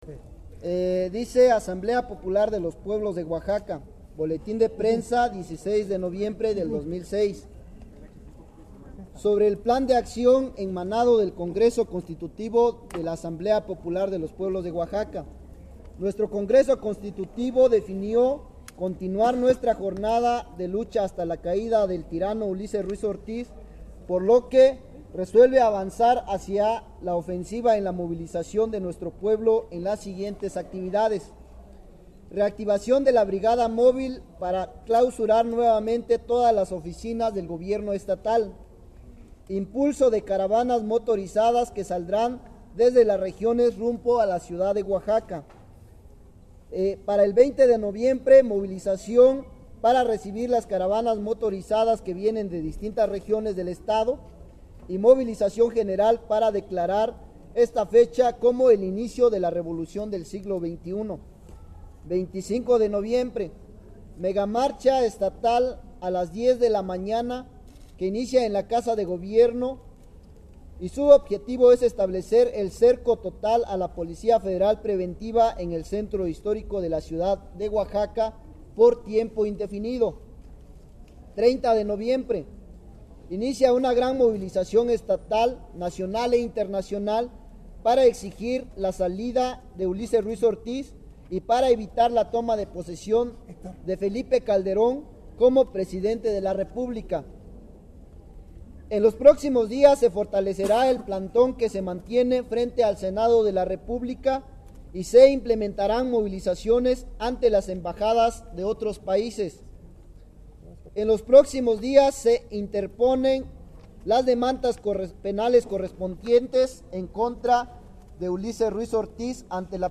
reads the APPO press release